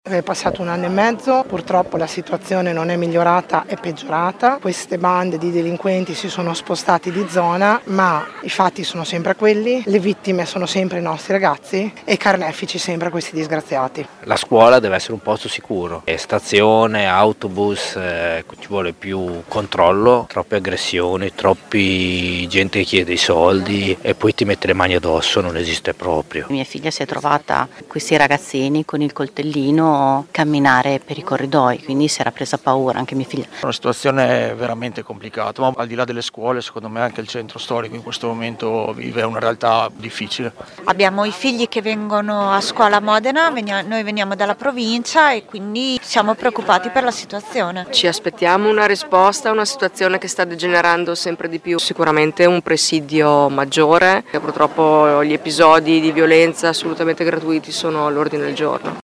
Sotto le interviste ad alcuni genitori presenti all’incontro